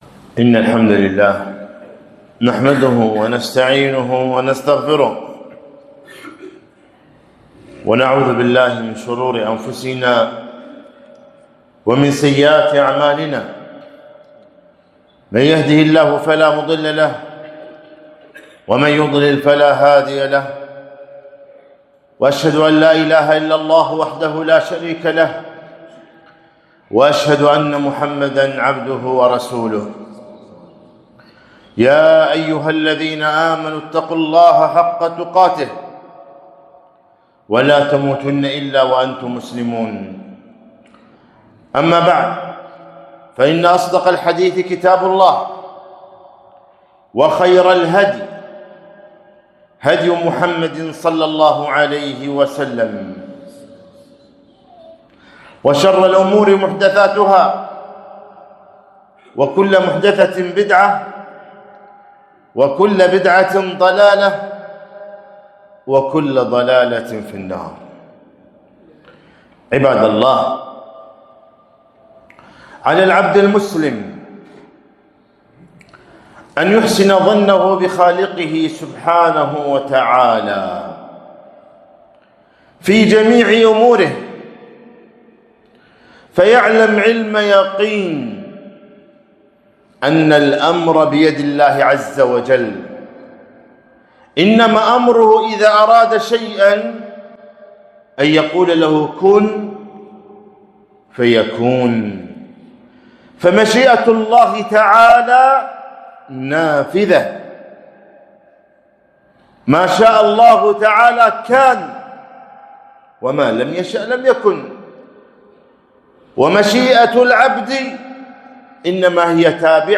خطبة - التفاؤل في حياة المسلم